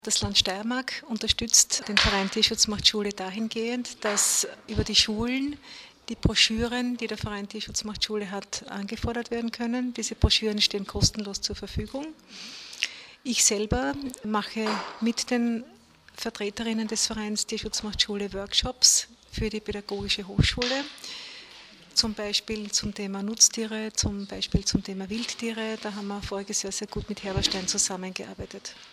Graz (5. Juni 2013).- Gemeinsam mit dem zuständigen Landesrat Gerhard Kurzmann präsentierte die steirische Tierschutzombudsfrau Barbara Fiala-Köck heute Vormittag (05.06.2013) im Medienzentrum Steiermark ihren aktuellen Tätigkeitsbericht.